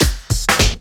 Shuffle FX.wav